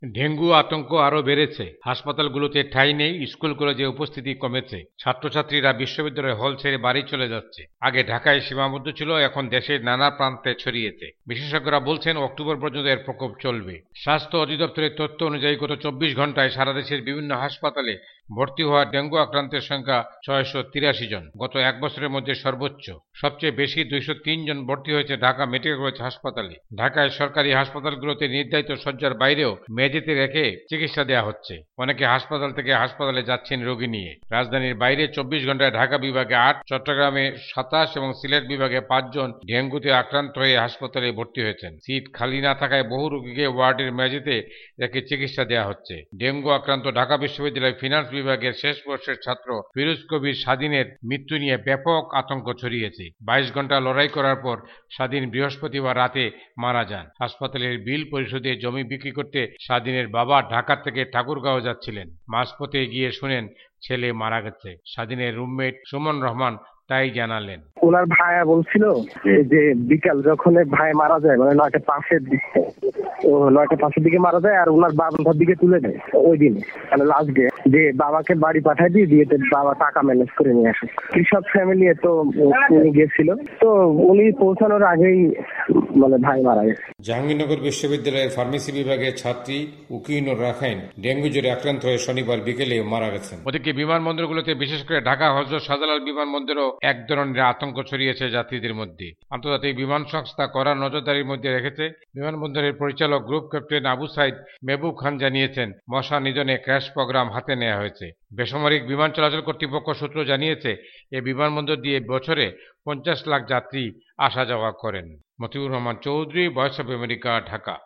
ঢাকা থেকে
রিপোর্ট।